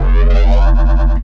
Bass 1 Shots (99).wav